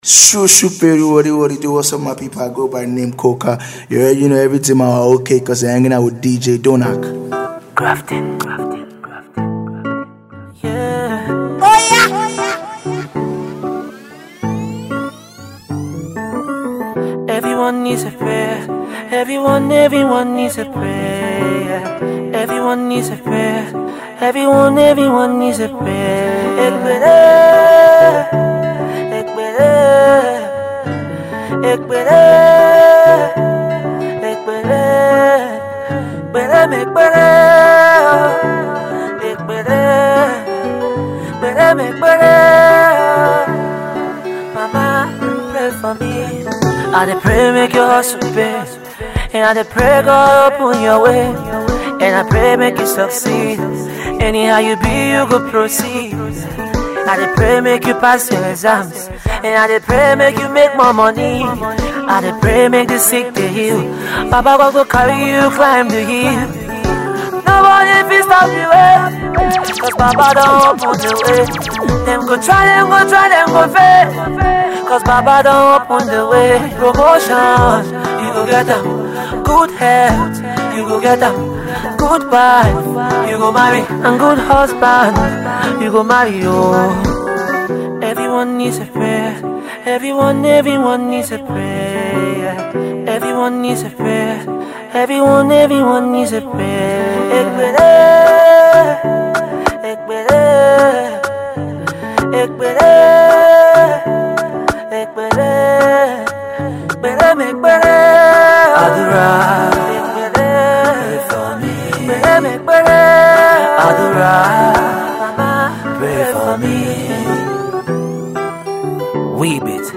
non-stop mixtape
Packed with a lot of inspirational, yet dance-able tunes.